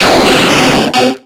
Cri de Krabboss dans Pokémon X et Y.